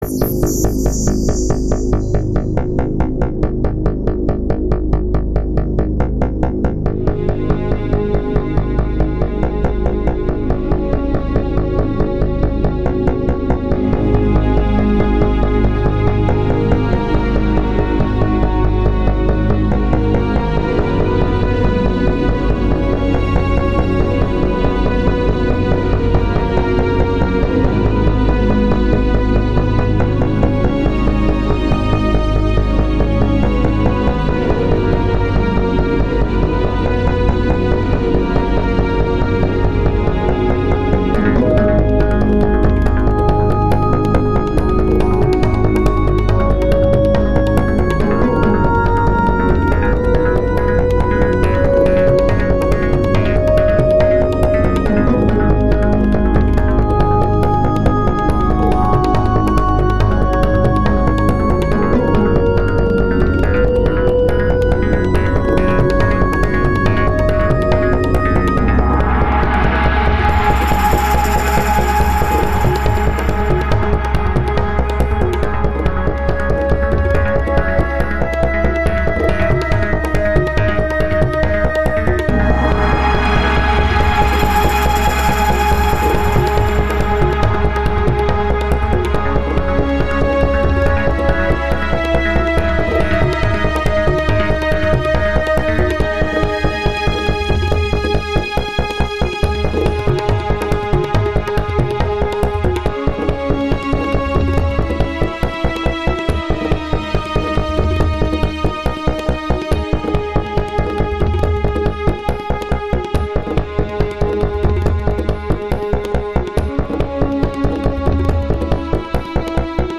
Main menu song.
Needs some extra flavour, but it has a nice ambience.